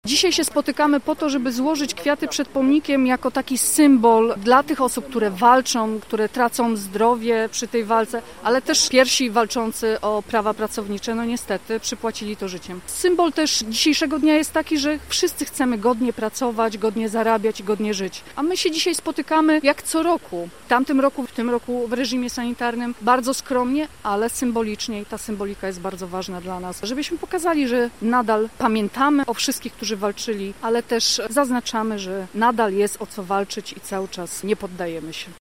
Przedstawiciele lubuskiej Lewicy jak co roku zebrali się pod Pomnikiem Bohaterów II Wojny Światowej na placu Bohaterów w Zielonej Górze by uczcić Święto Pracy i przypadającą dziś 17 -tą rocznicę wejścia Polski do Unii Europejskiej.